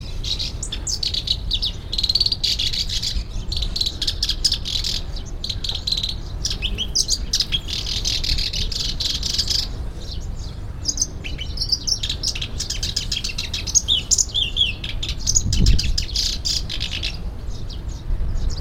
Yangkou & Dongtai, Jiangsu
And here is the recording of Black-browed Reed Warbler (00:19; 1.5 MB):
warbler-black-browed-reed001-Acrocephalus-bistrigiceps.mp3